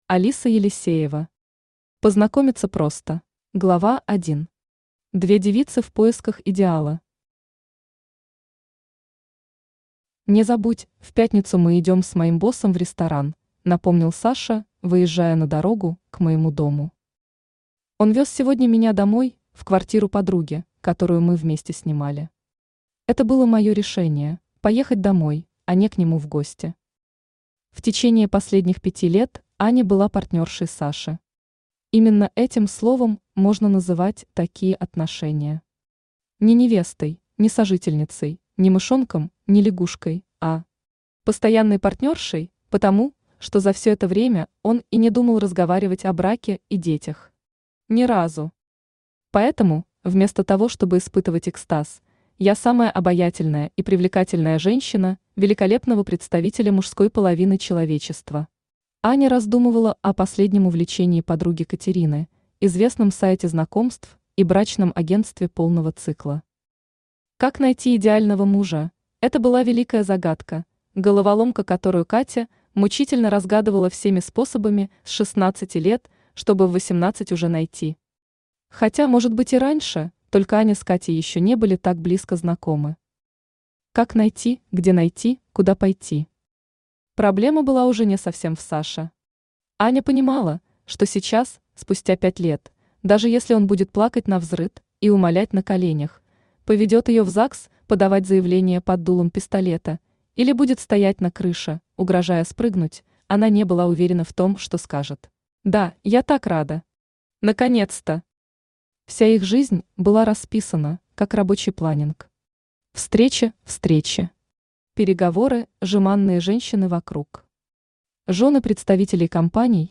Аудиокнига Познакомиться просто | Библиотека аудиокниг
Aудиокнига Познакомиться просто Автор Алиса Елисеева Читает аудиокнигу Авточтец ЛитРес.